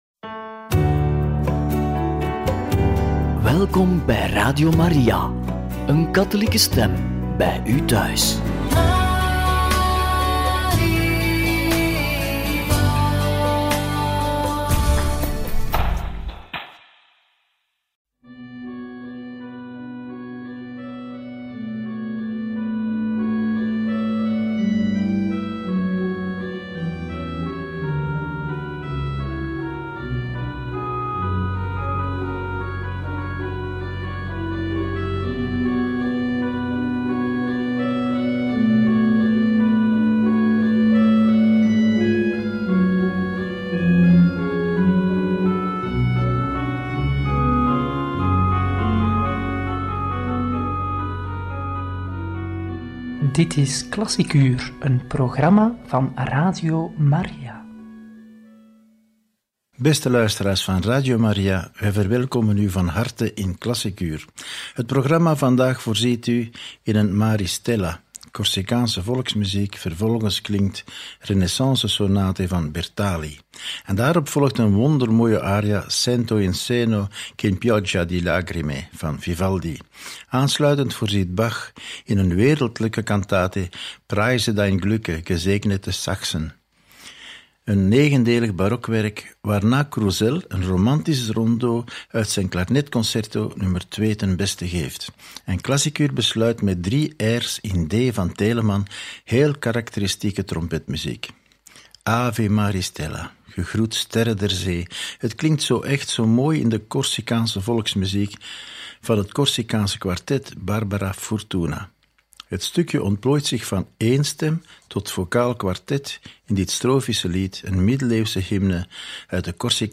Een ‘Maris Stella’, klarinetconcerto, trompetmuziek en veel meer! – Radio Maria